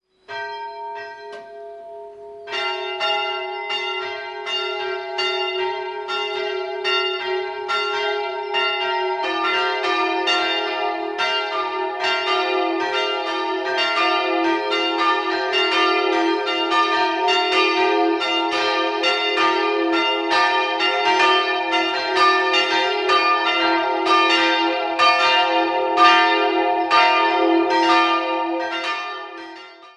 3-stimmiges TeDeum-Geläute: d''-f''-g''
Große Glocke
Mittlere Glocke
Sulzbuerg_Marktkirche.mp3